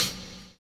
Closed Hats
DILLA-HH-0030.wav